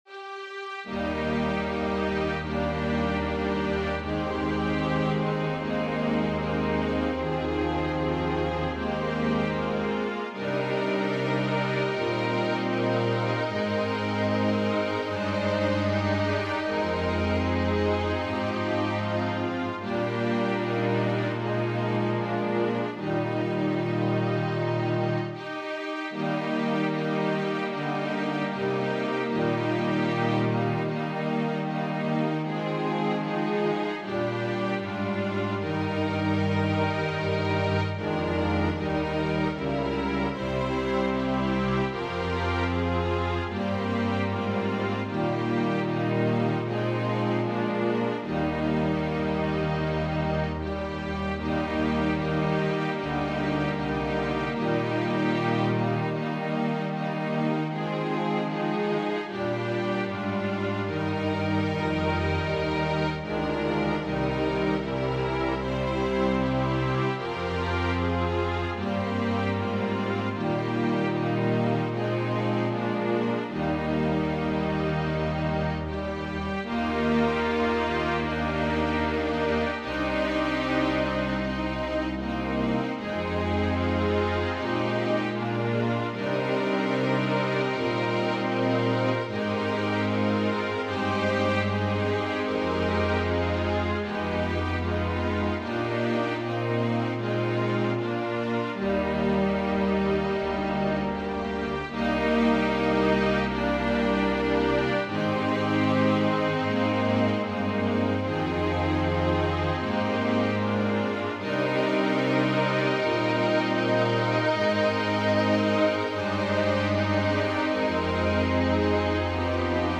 So I modified for two extra verses at the end to give them extra time to prep it.
Voicing/Instrumentation: Organ/Organ Accompaniment We also have other 34 arrangements of " There is a Green Hill Far Away ".